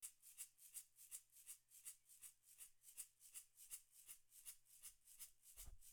Shaker Wilshire.wav